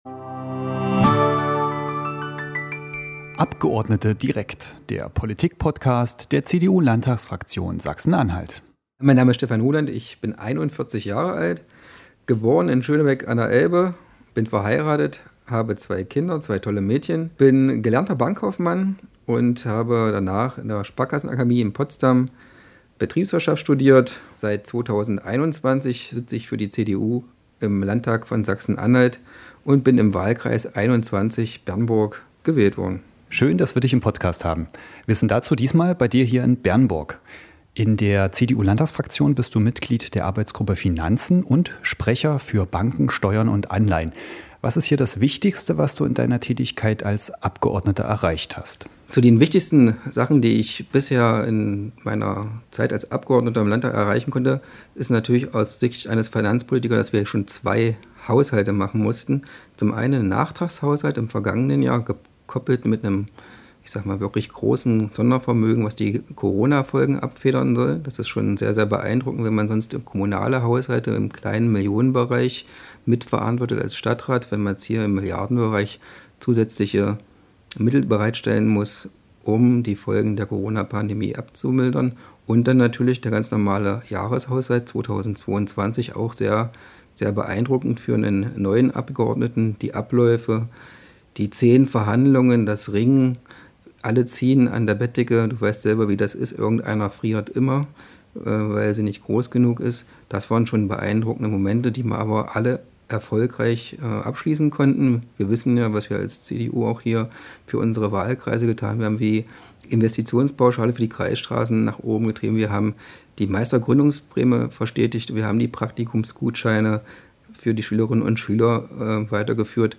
Podcast spricht der Finanzpolitiker über seine